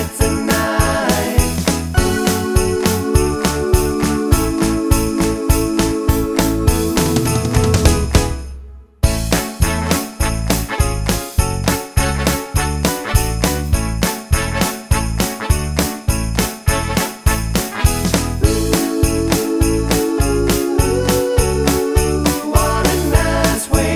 No Saxophone Pop (1980s) 3:38 Buy £1.50